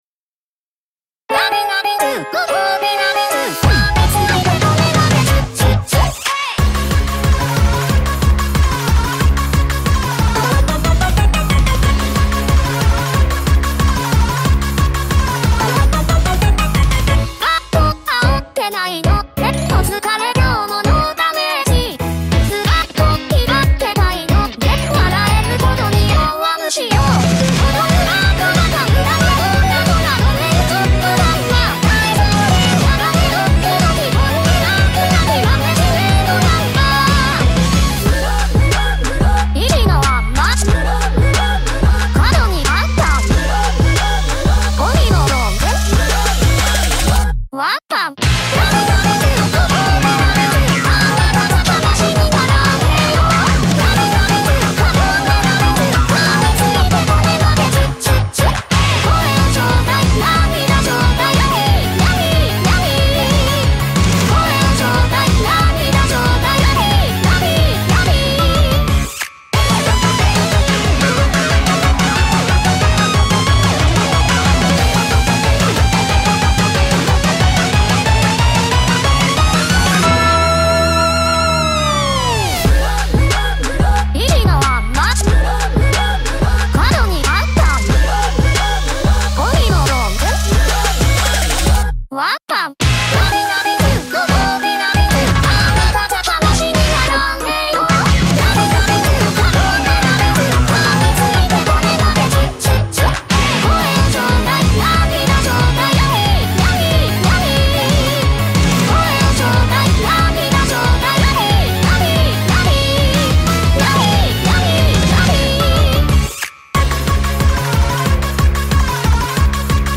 BPM92-183
Audio QualityPerfect (High Quality)